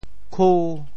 Details of the phonetic ‘ku5’ in region TeoThew